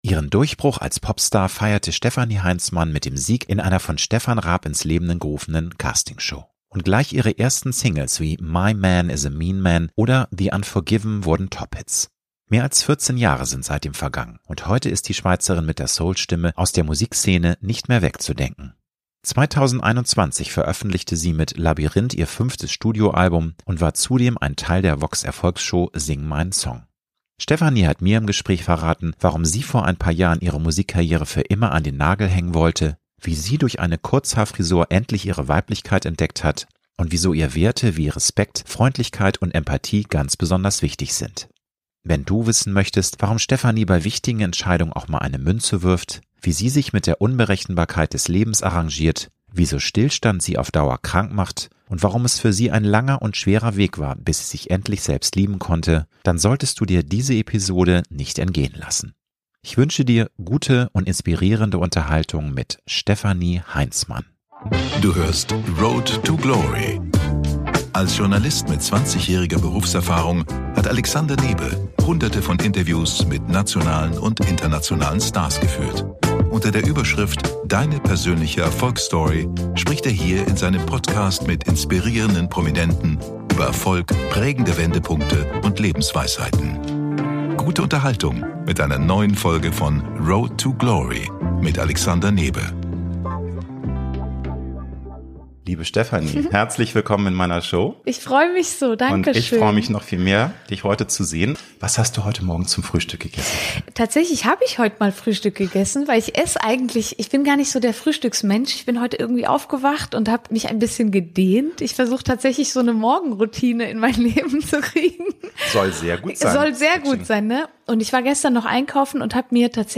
Ich wünsche dir gute und inspirierende Unterhaltung mit Stefanie Heinzmann!